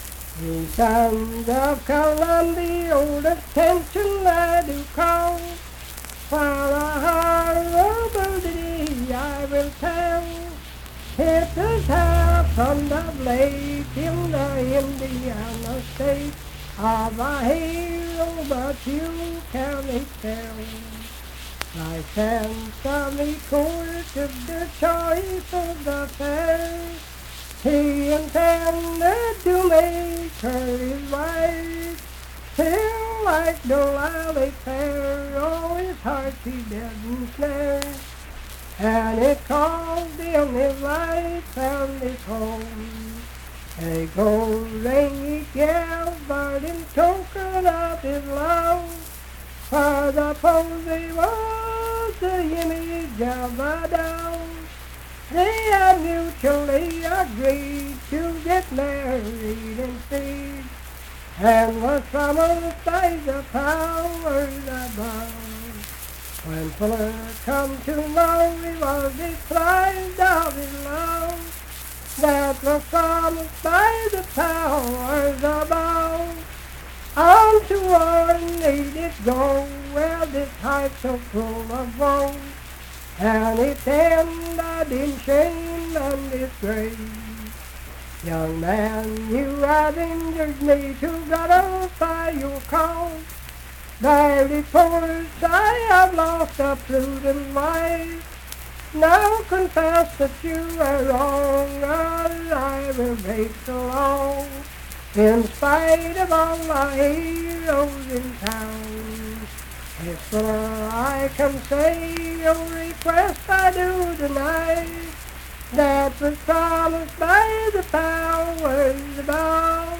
Unaccompanied vocal music
Performed in Ivydale, Clay County, WV.
Voice (sung)